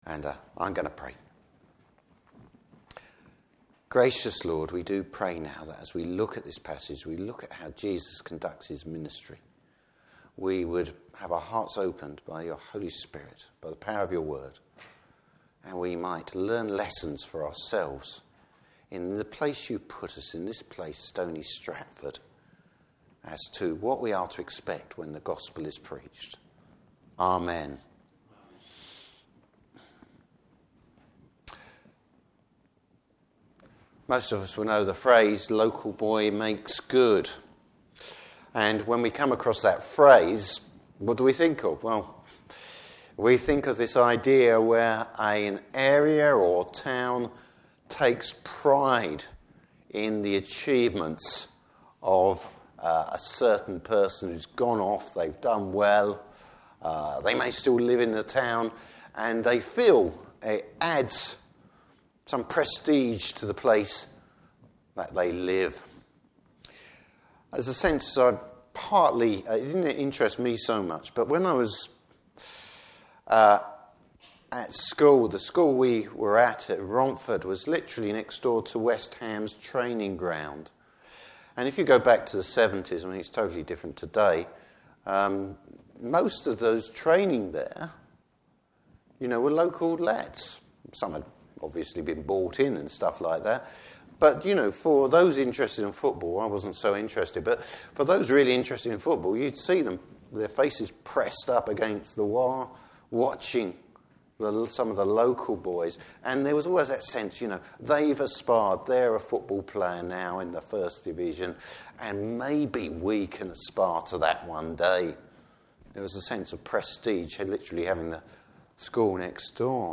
Service Type: Morning Service Bible Text: Luke 4:13-30.